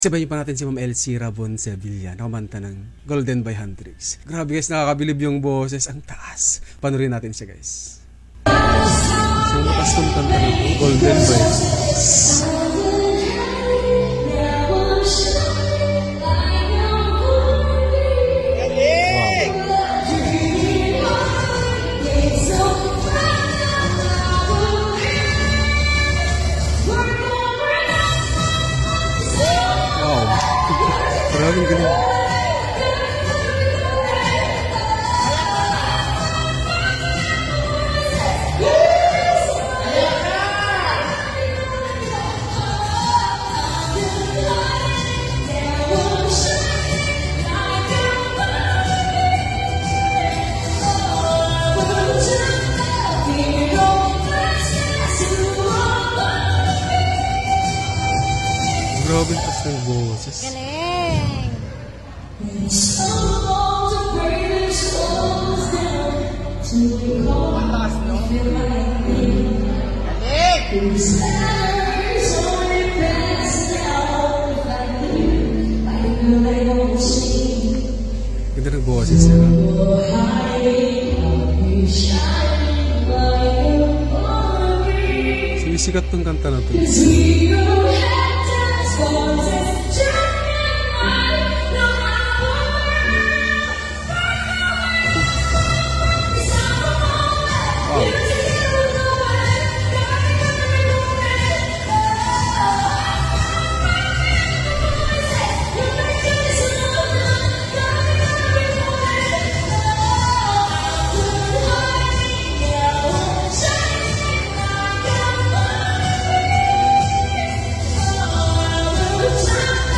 ang ganda ng pagkakanta niya at ang taas ng boses.